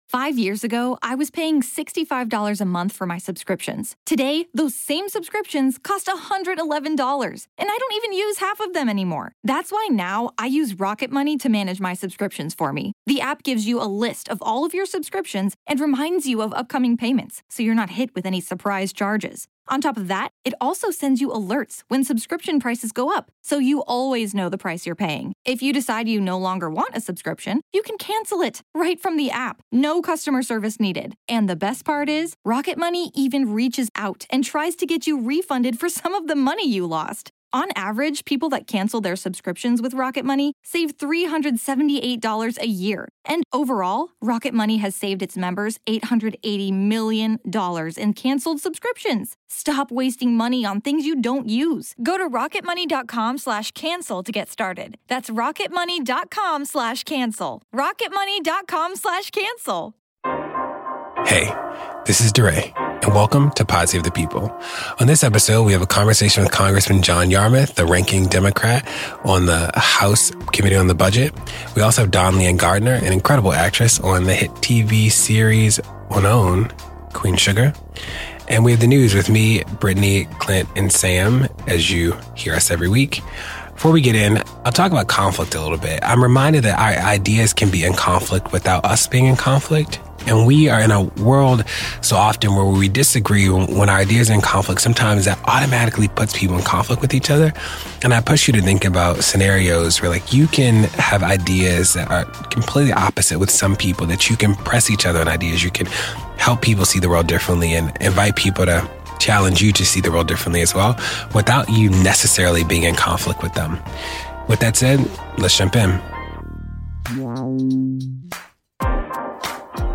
DeRay and Congressman John Yarmuth (D-KY) help us understand the federal budget, including the latest debt ceiling deal among Trump, Pelosi and Schumer, how the DNC is preparing for 2020, and the opioid crisis in KY. Actress Dawn-Lyen Gardner from Queen Sugar joins DeRay to talk about being an activist actress and what it’s like have Ava and Oprah as mentors.